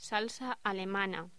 Locución: Salsa alemana
voz
locución
Sonidos: Voz humana